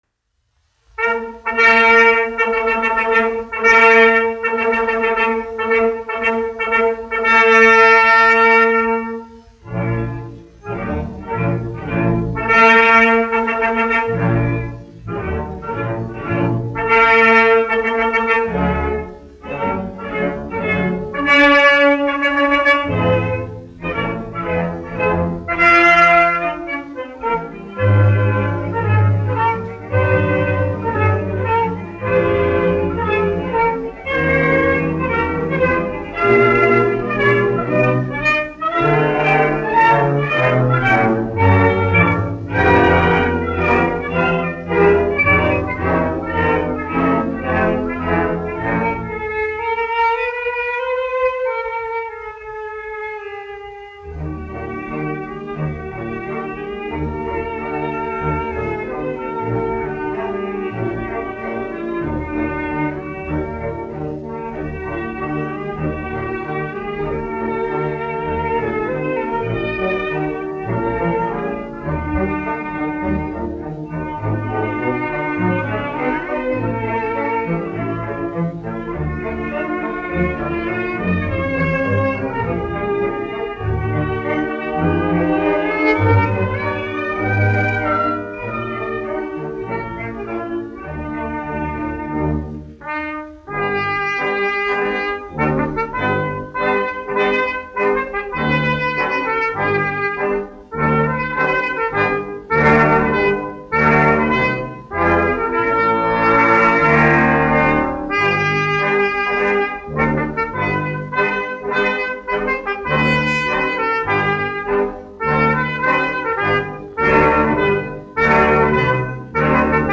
Symphonisches Orchester Berlin, izpildītājs
1 skpl. : analogs, 78 apgr/min, mono ; 25 cm
Operas--Fragmenti
Marši
Skaņuplate